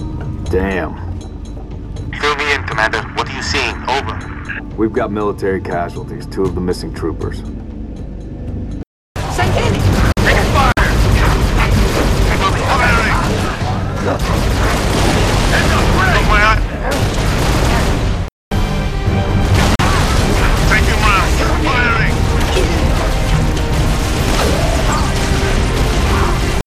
It plays continuously on a loop and there’s no option to “switch off” the music.
I’m adding a sample of the audio for scenes firstly without action, then secondly and thirdly with action (they’ll be in the same file).
I’ve uploaded the two samples, before and afterwards using the effects, in the same file